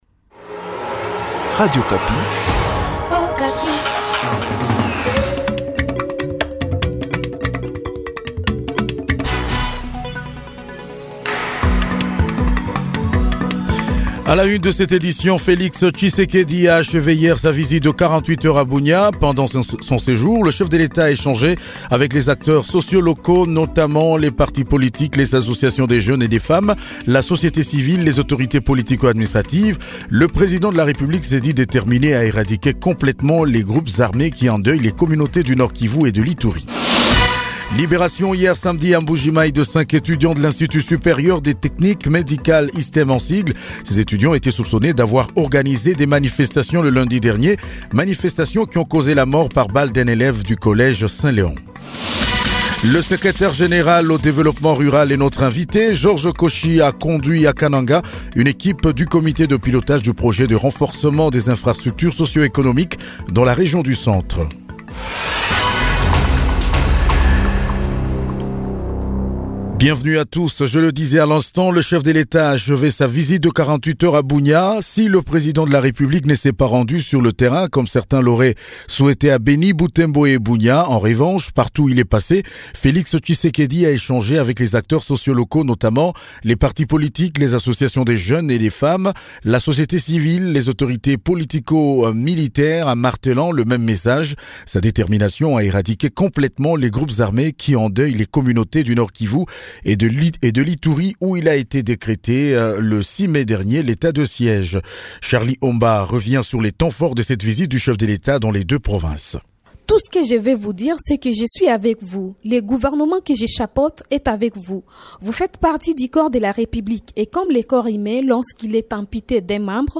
JOURNAL MIDI DU 20 JUIN 2021